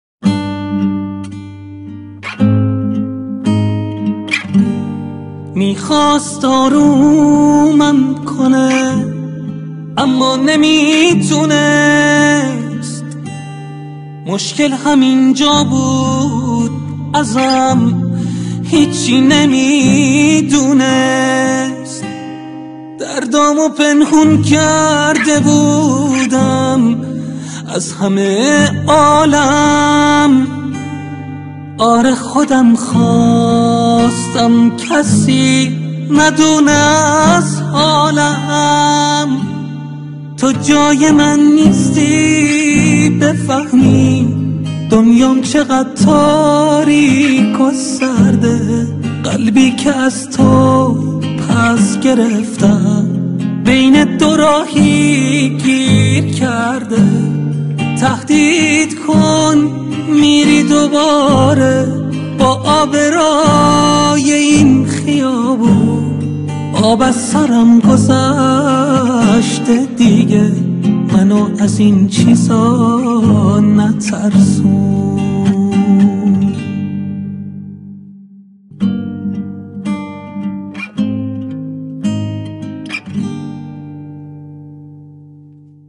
دسته بندی : دانلود آهنگ غمگین تاریخ : دوشنبه 16 سپتامبر 2019